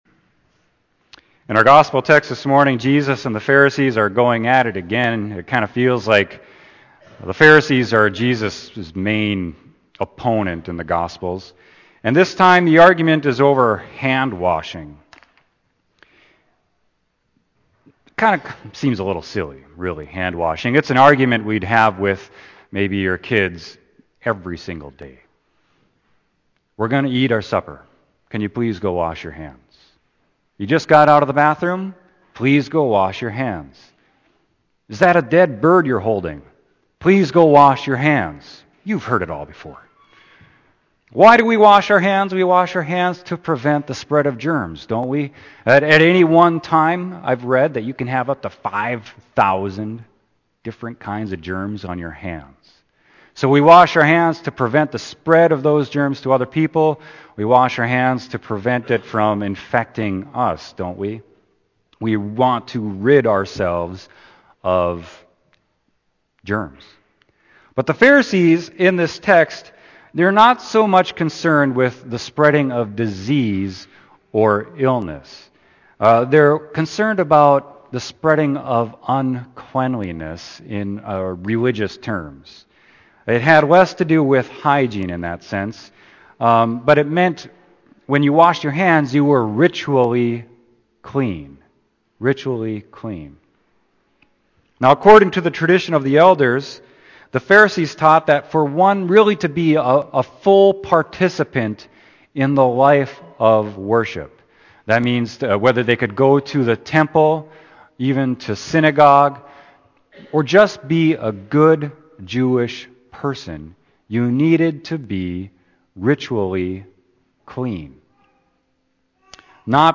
Sermon: Mark 7.1-8, 14-15, 21-23